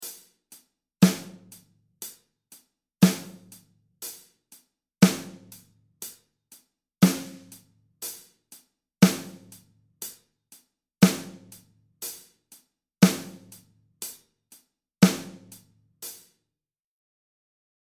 try a 4/4 rock beat:
Rock Beat
rock-drum-beat.mp3